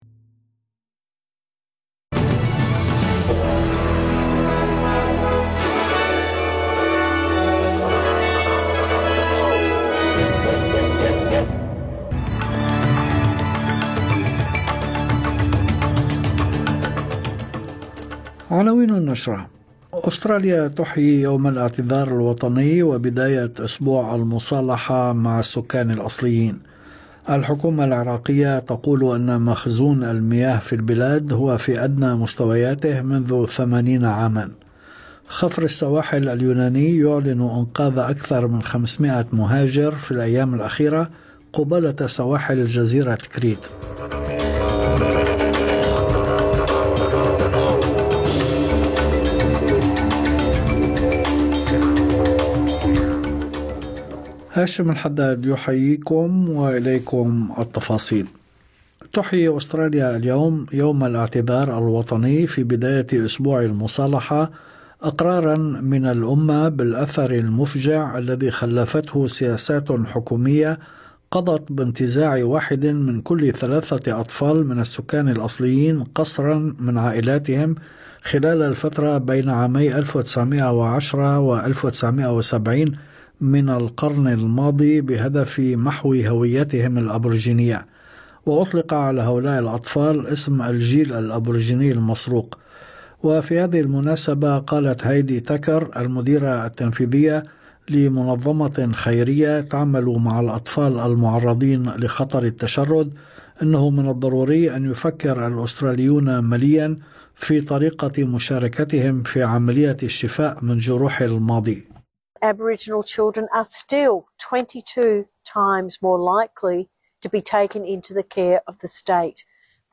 نشرة أخبار المساء 26/5/2025